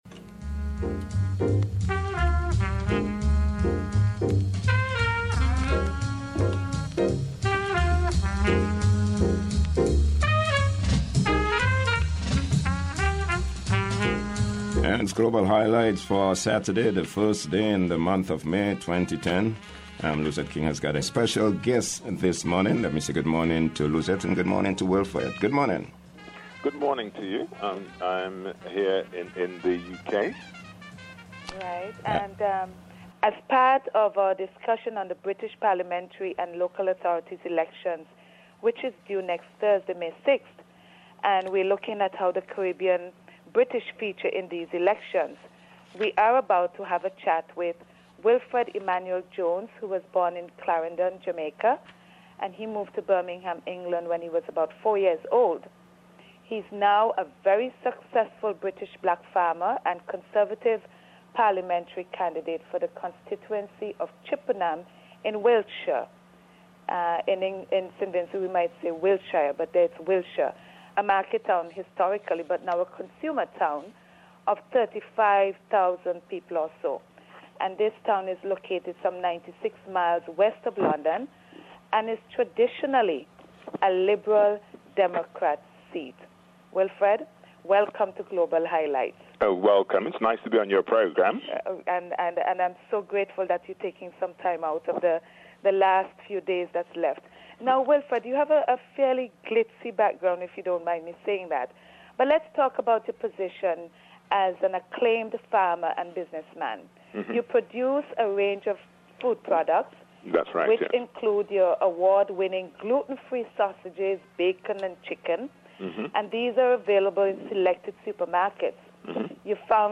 The interview wit